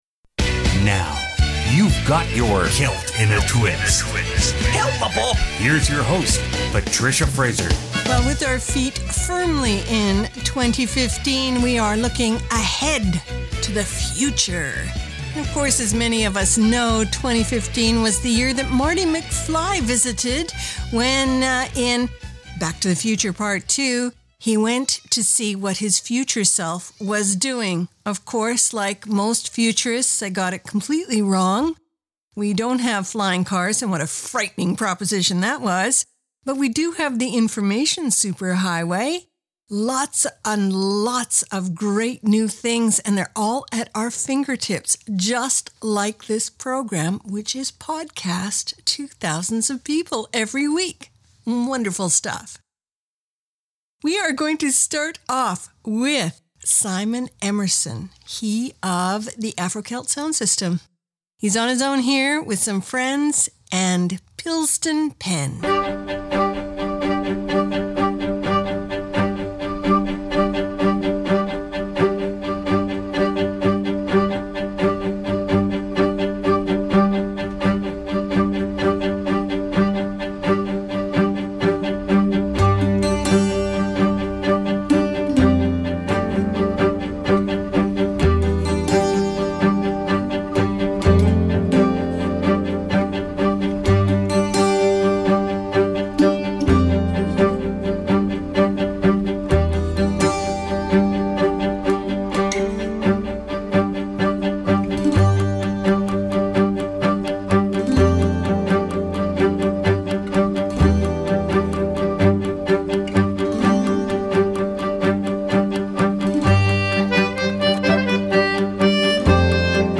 Canada' Contemporary Celtic Radio Hour
File Information Listen (h:mm:ss) 0:58:26 Celt In A Twist January 11 2015 Download (5) Celt_In_A_Twist_January_11_2015.mp3 70,125k 0kbps Stereo Comments: Celtic that speaks volumes w/ nary a word-an hour of instro delights+a few choice verses.